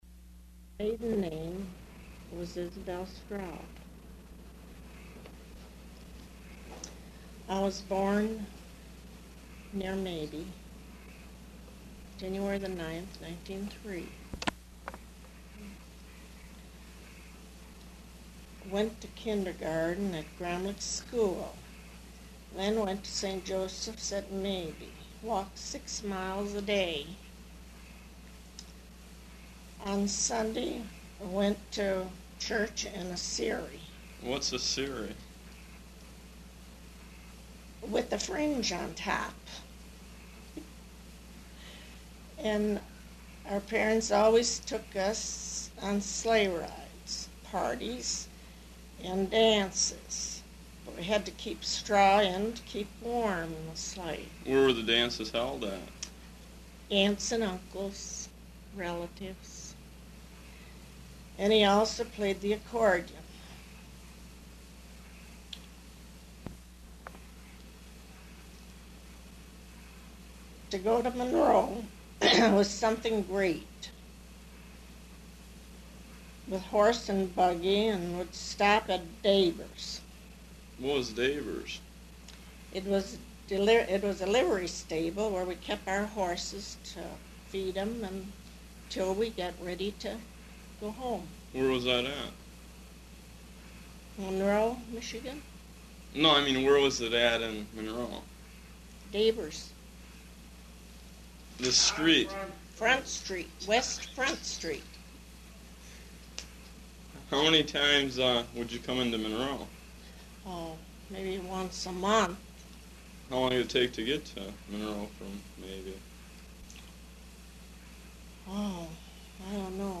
Recorded oral history
Subjects Prohibition Business enterprises Michigan--Monroe County Material Type Interviews